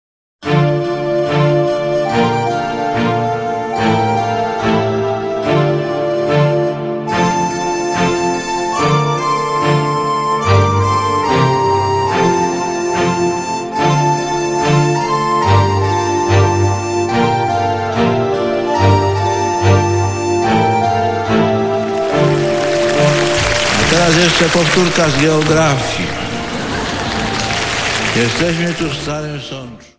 Nauczyliśmy się naszej Ojczyzny - wypowiedzi Ojca Świętego do rodaków
We've Learned Our Homeland - The Holy Father's Statements to His Countrymen 1978—2002